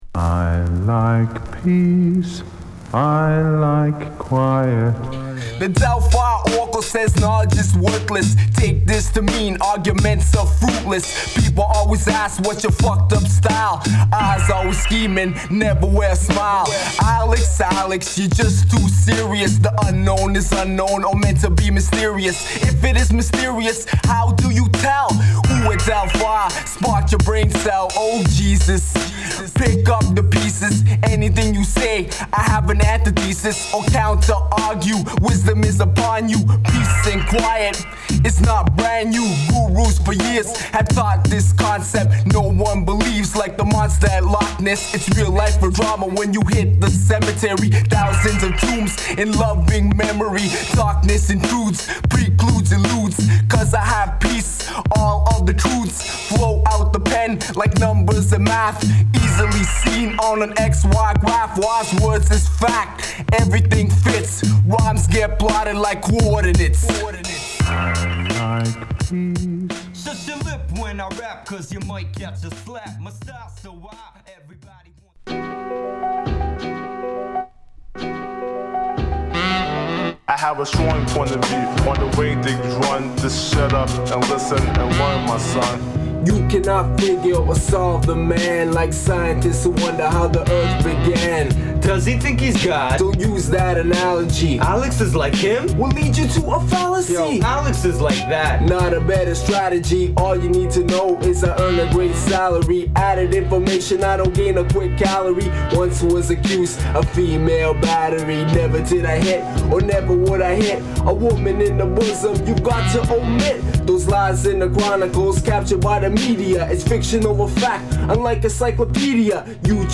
今回の93年〜94年頃に録音された音源が